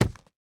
Minecraft Version Minecraft Version snapshot Latest Release | Latest Snapshot snapshot / assets / minecraft / sounds / block / chiseled_bookshelf / insert1.ogg Compare With Compare With Latest Release | Latest Snapshot